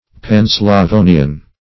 Panslavonian \Pan`sla*vo"ni*an\, a.